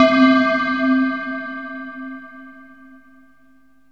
AMBIENT ATMOSPHERES-3 0007.wav